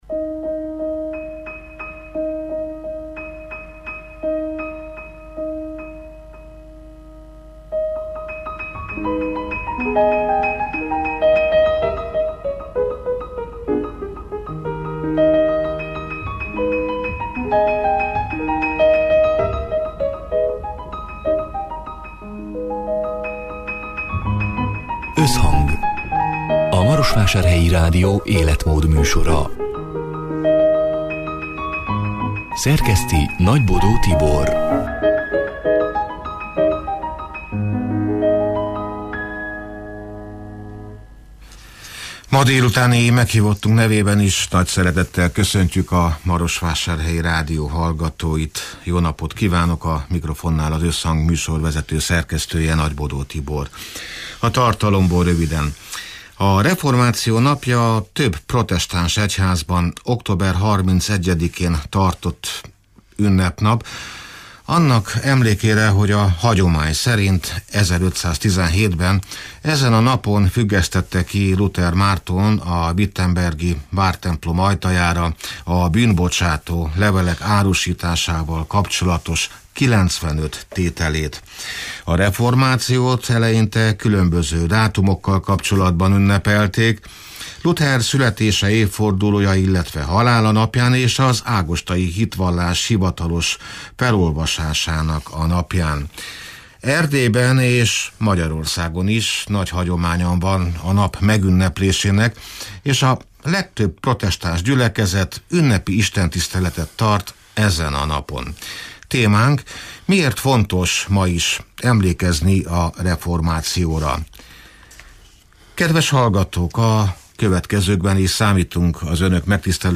(elhangzott: 2024. október 30-án, szerdán délután hat órától élőben)